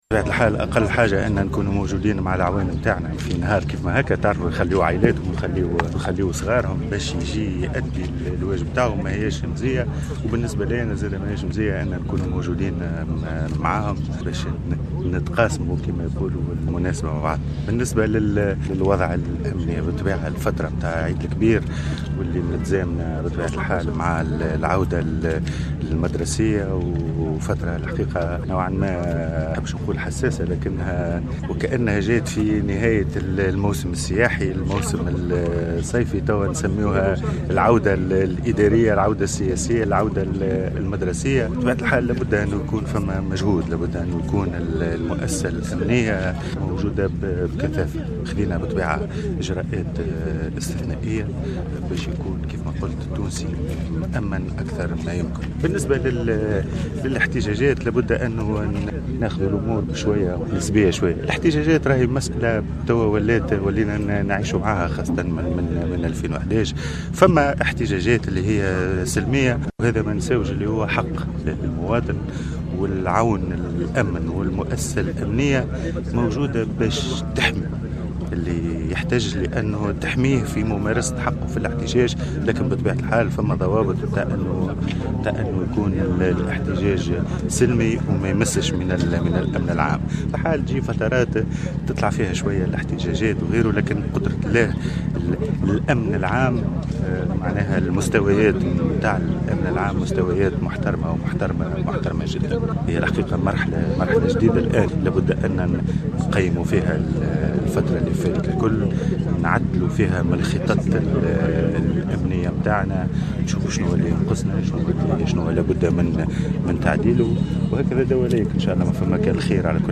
قال وزير الداخلية الهادي المجدوب خلال زيارة تفقدية أداها اليوم الاثنين 12 سبتمبر 2016 إلى مختلف الوحدات الأمنية بالعاصمة إنه قد تم اتخاذ إجراءات استثنائية لتأمين الاحتفال بعيد الأضحى والعودة المدرسية.
وأضاف المجدوب في تصريح اعلامي أن الوضع الأمني العام جيد والجاهزية الأمنية ممتازة وفي تحسن وفق تعبيره.